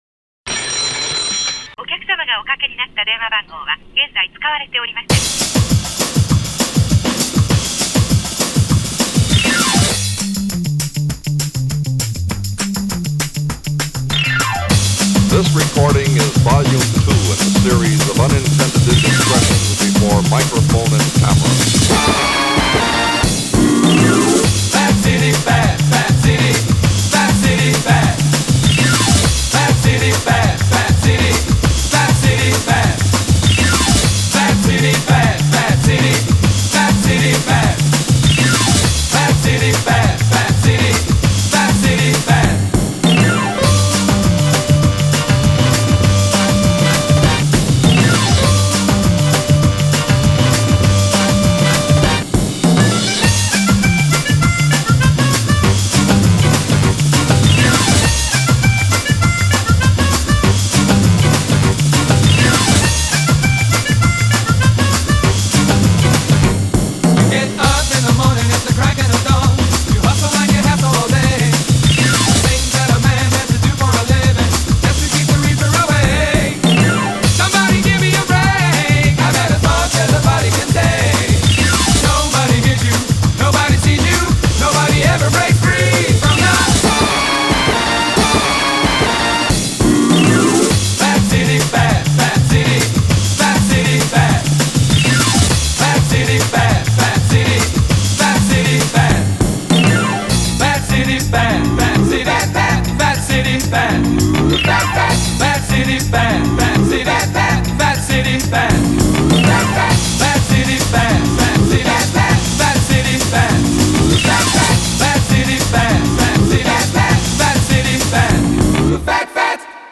BPM100-200
Audio QualityPerfect (High Quality)
Genre: BOSSA GOGO.